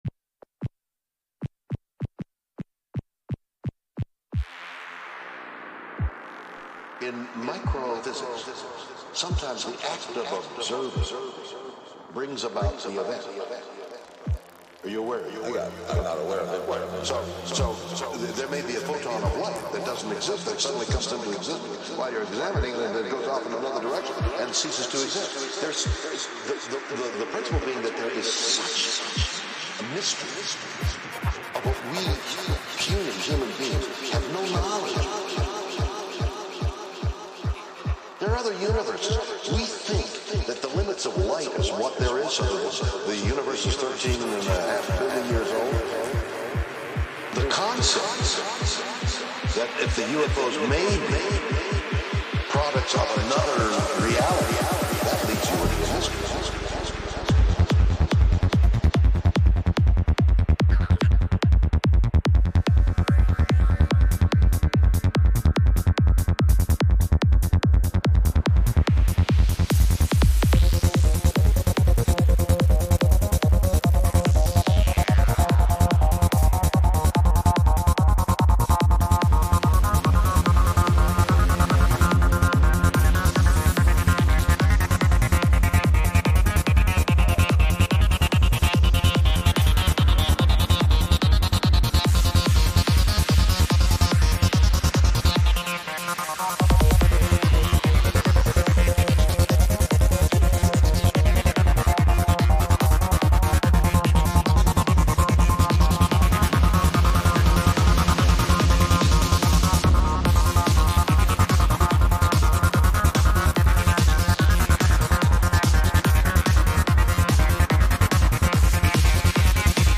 Psytrance from 2017 and 2020 with some uplifting at the end.
Psy Trance Trance Hard Trance